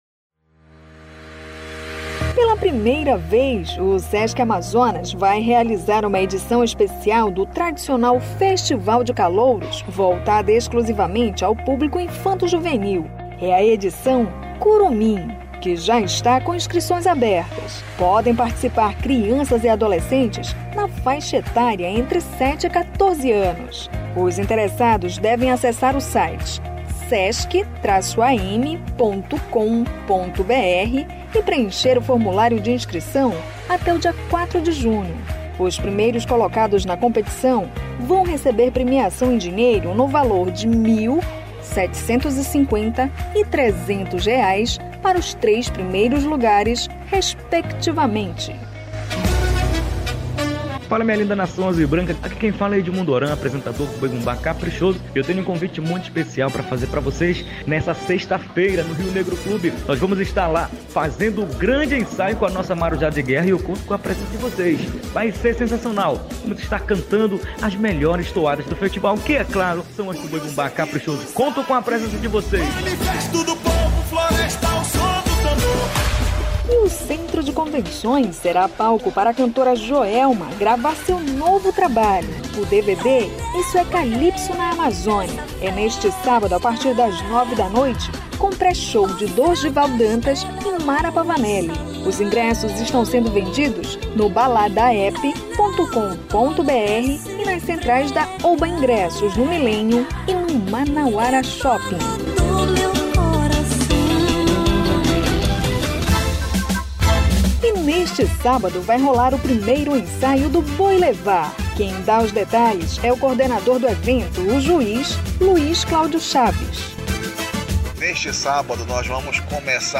Reportagem do dia